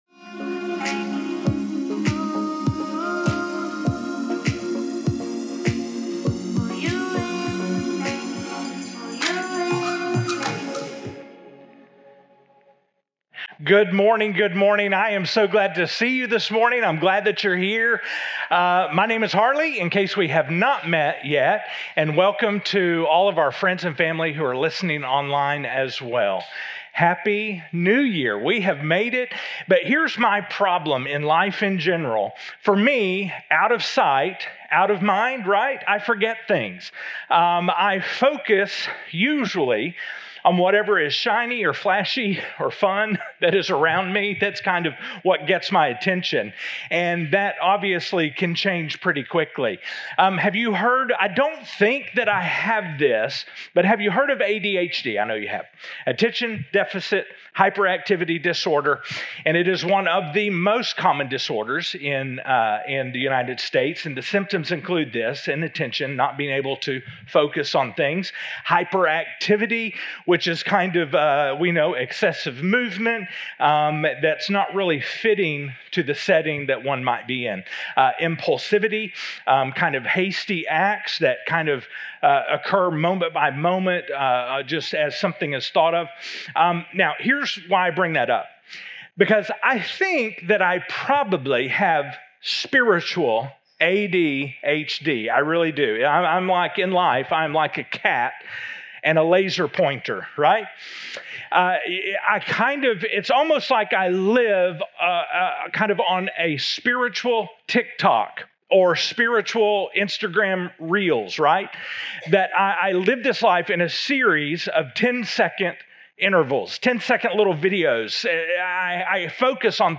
Teaching Audio | Stuttgart Harvest Church and The Church In Malvern